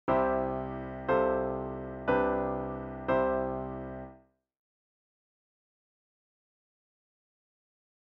A pedal tone is a tone that repeats throughout a chord progression.
You’ll notice that the note C is repeated as the lowest sounding tone in each chord, even in the G chord, which normally doesn’t have a C. And the effect is very interesting.
C  F/C  G/C  C